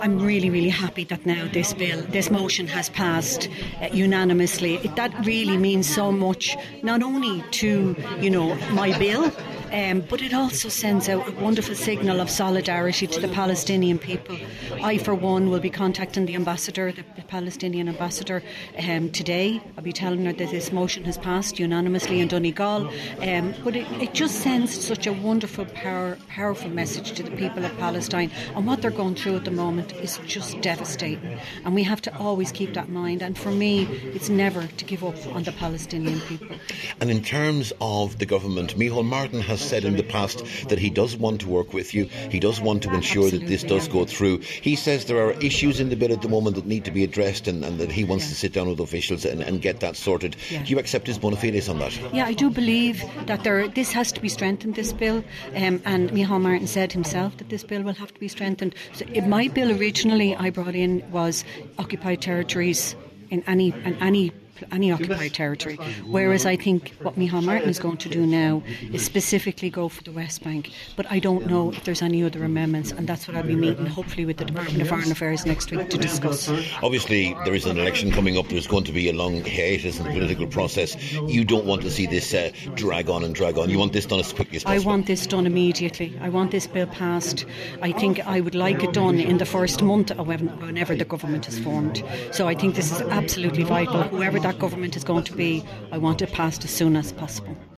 The bill was first introduced in the Seanad over six years ago by Senator Frances Black, who was in Lifford today, and addressed members after the motion was passed.
Speaking to Highland Radio News, she said support from local councils is very important: